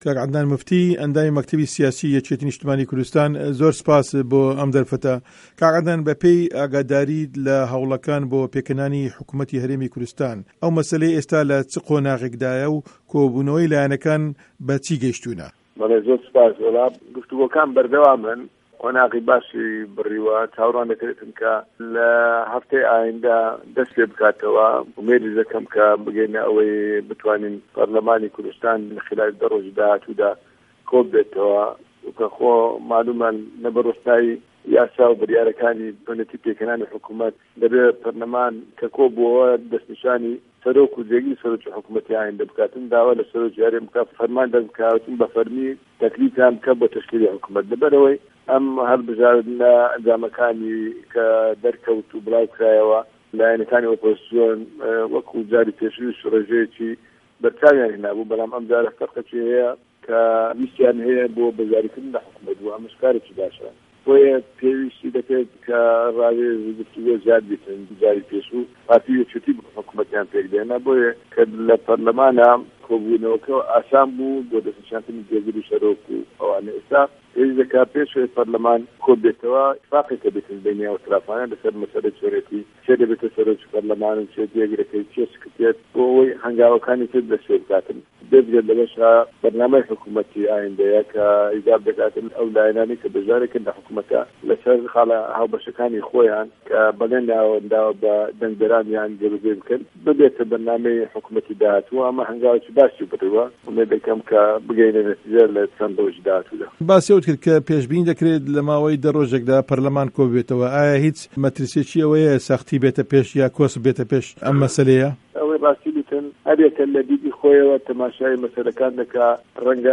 وتووێژی عه‌دنان موفتی